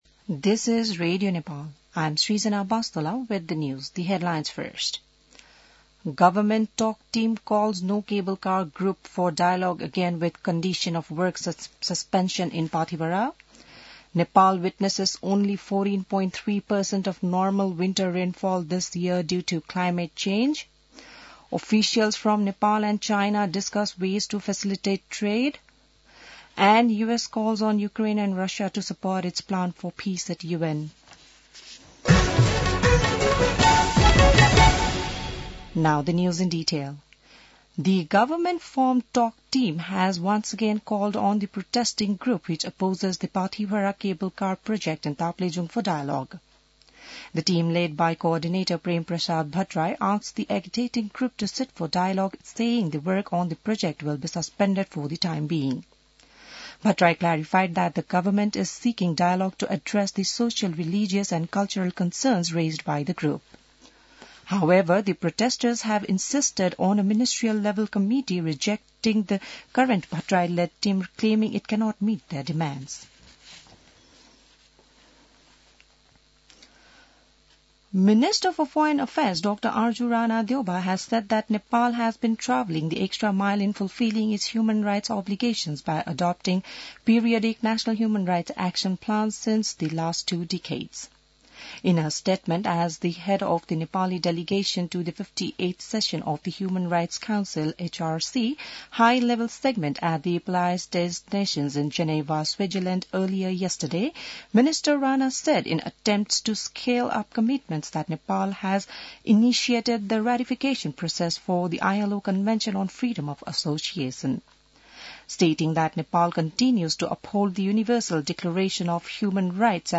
बिहान ८ बजेको अङ्ग्रेजी समाचार : १४ फागुन , २०८१